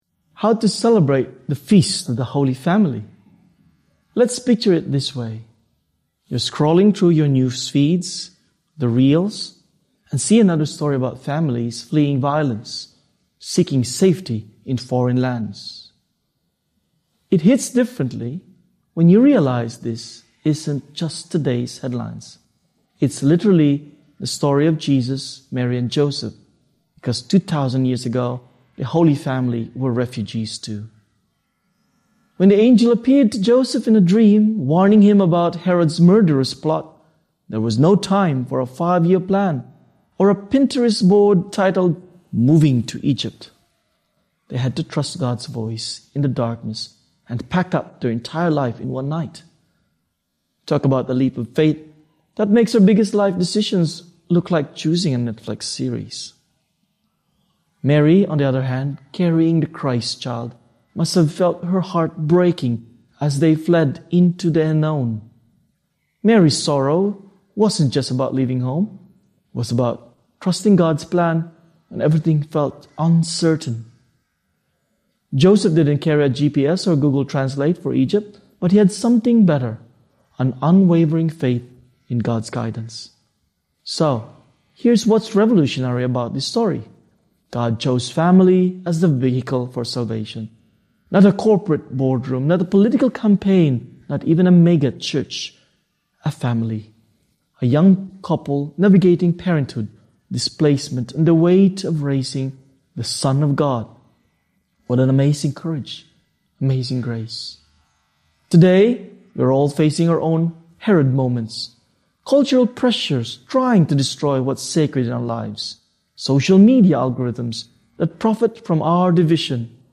Archdiocese of Brisbane Feast of the Holy Family - Two-Minute Homily